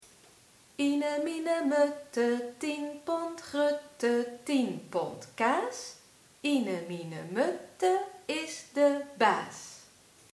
aftelrijmpje
Aftelversje (deels gezongen, deels opgezegd).